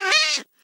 cat_hitt2.ogg